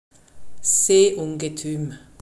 pronunciation)